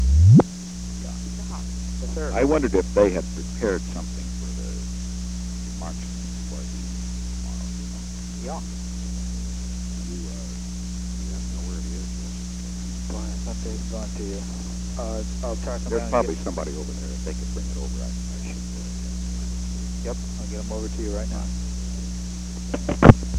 Secret White House Tapes
Conversation No. 20-101
Location: White House Telephone
The President talked with H. R. (“Bob”) Haldeman.